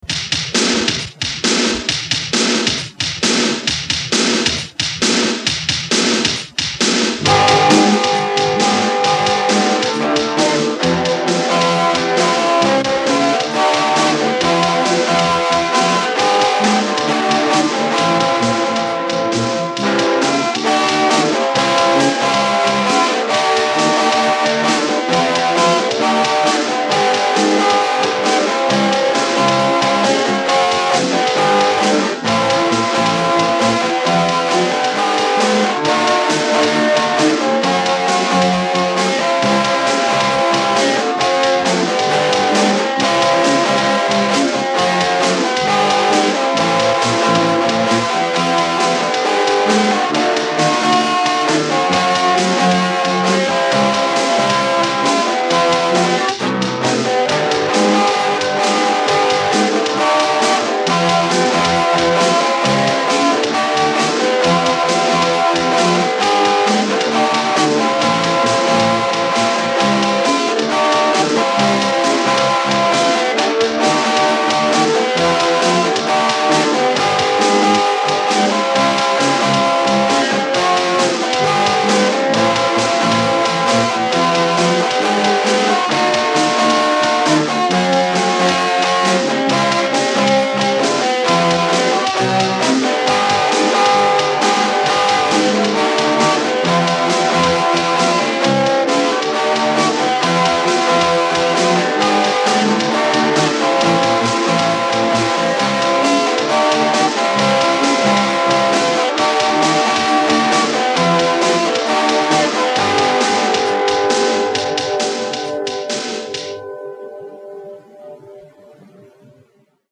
For sure I’m playing bass
Too bad the guitar wasn’t tuned so well 🙂